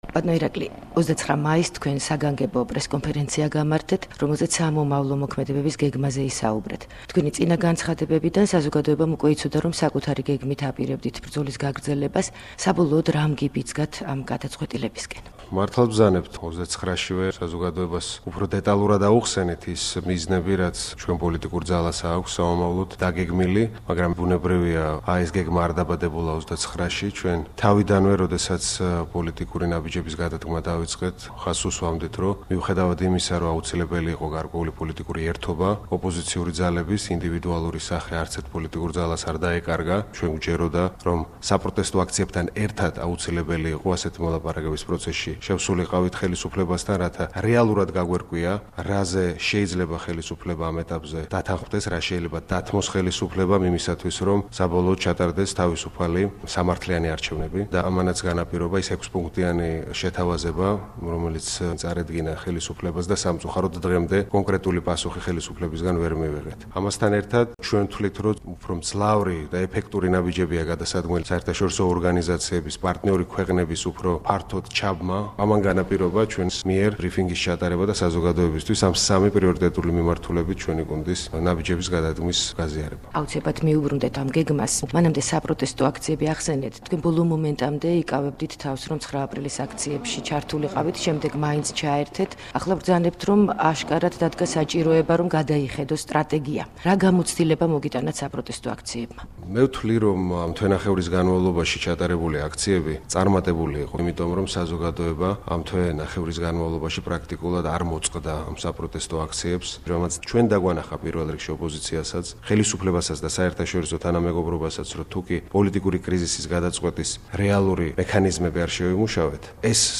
ალასანია ინტერვიუ